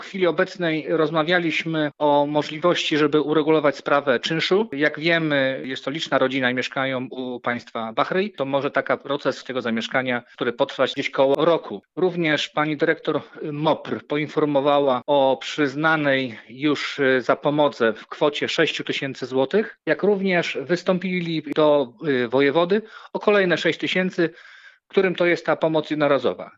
Mówi Radny Rady Miejskiej w Świnoujściu Sławomir Nowicki.